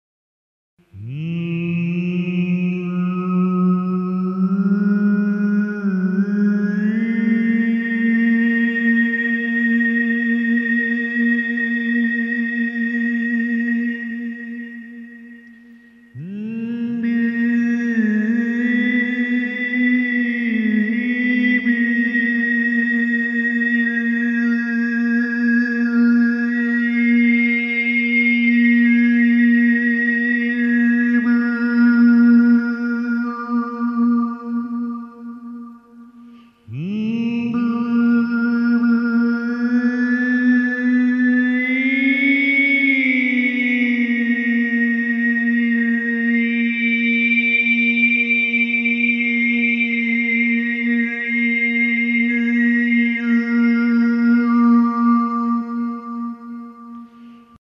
Erste Verstärkungstechnik – die L-Technik
Hörprobe Obertonsingen mit L-Technik
Mit der L-Technik lassen sich gut die Harmonischen H4 bis H16 singen.